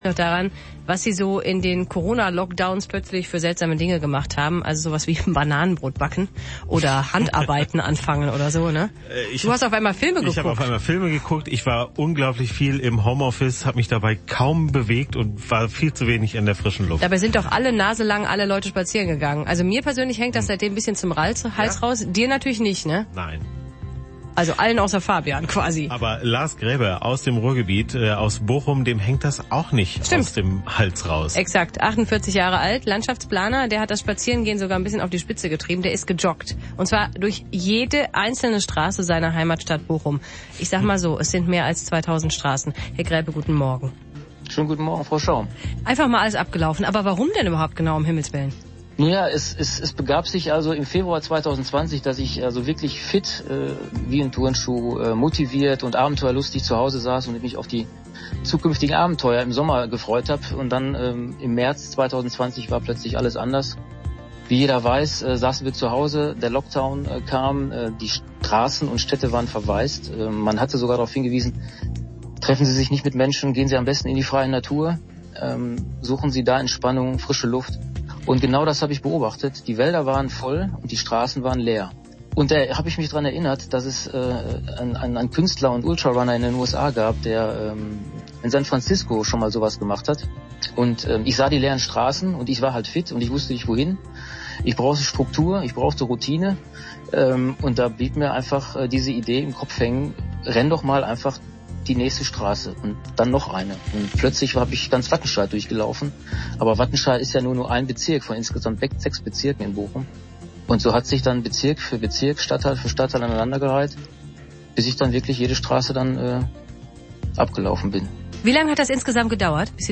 WDR 2 Morgenmagazin – Radiointerview